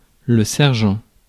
ÄäntäminenFrance:
• IPA: [lə sɛʁ.ʒɑ̃]